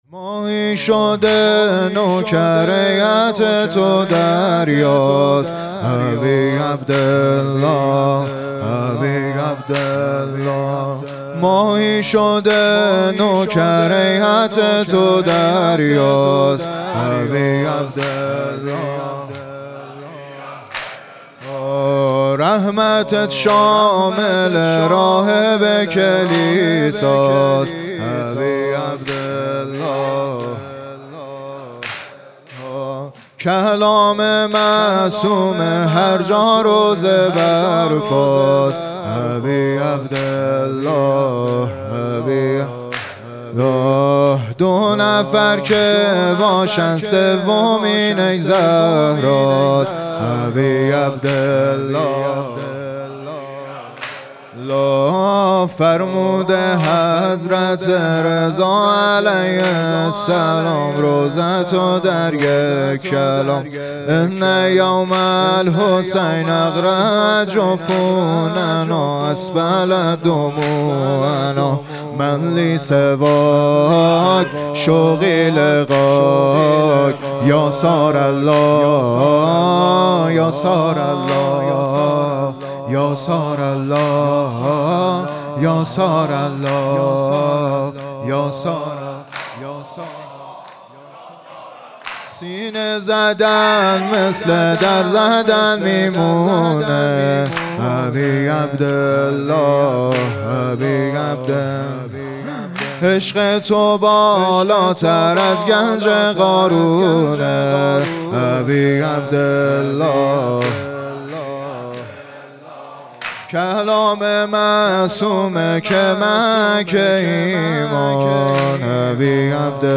واحد تک شب اول محرم 1403
شب اول محرم الحرام 1446